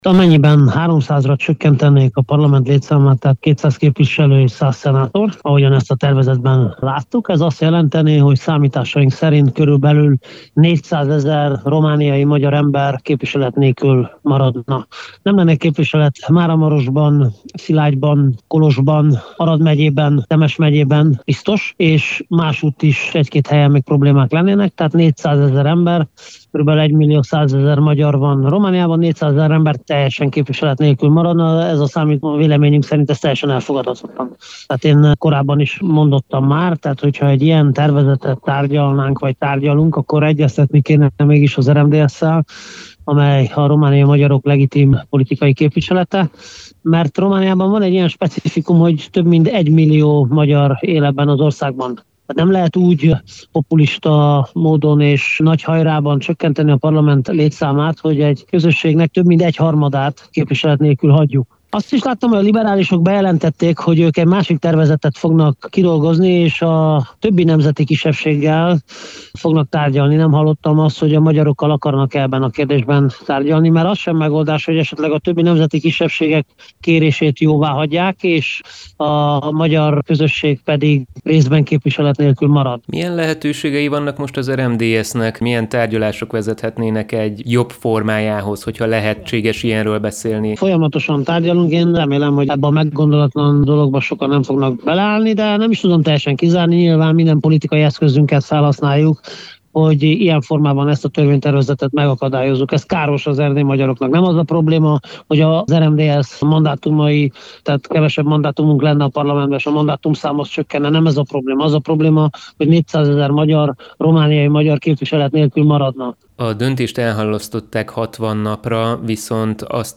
Több megyében is megszűnhet a magyar képviselet, amennyiben a kormány elfogadja a törvényhozók létszámát csökkentő javaslatot – figyelmeztetett a Kolozsvári Rádiónak adott interjújában Csoma Botond, az RMDSZ szóvivője.